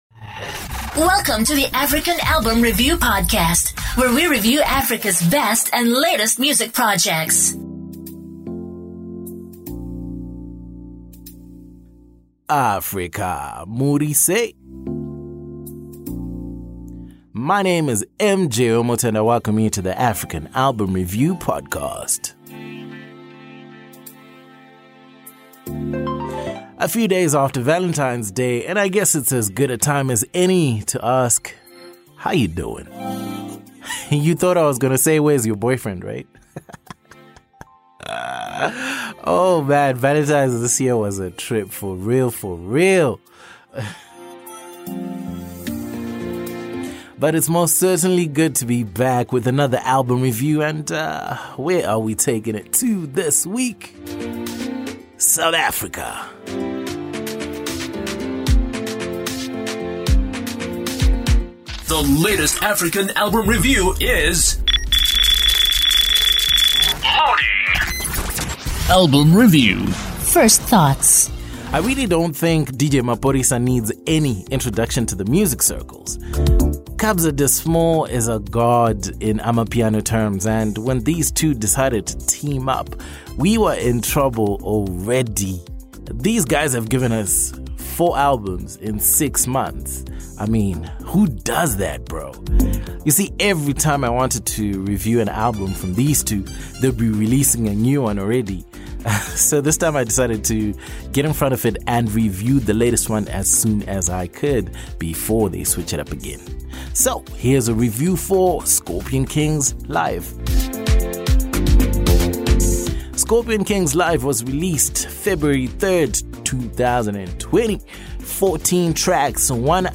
DJMAPHORISAKABZAAlbumReview-mixdown.mp3